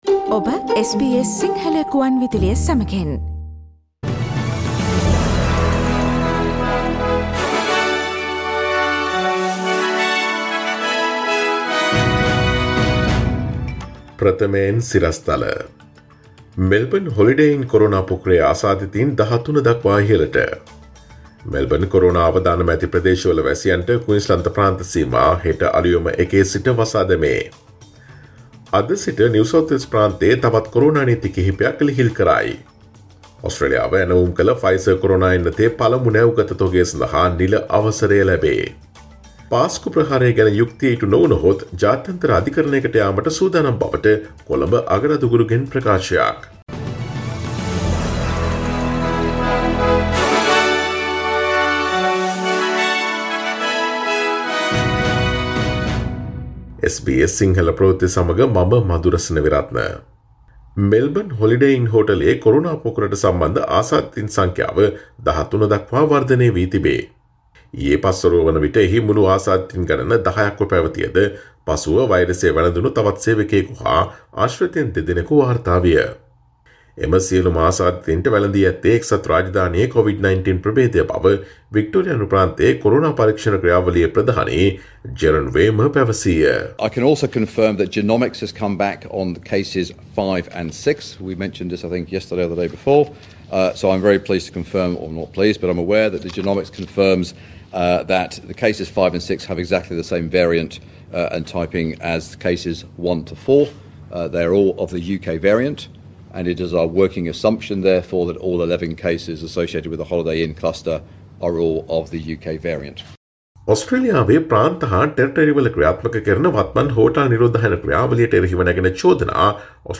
Today’s news bulletin of SBS Sinhala radio – Friday12 February 2021.